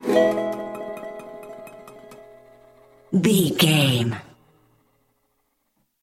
Ionian/Major
acoustic guitar
electric guitar
ukulele
dobro
slack key guitar